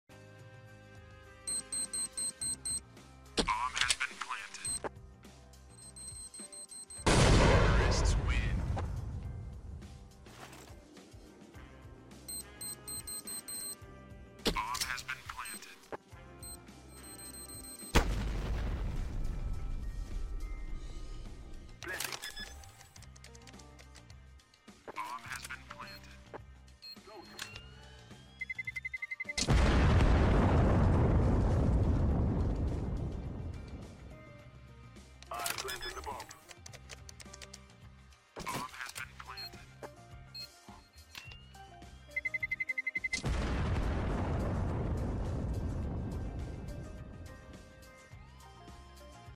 1.6 x CSS x CSGO sound effects free download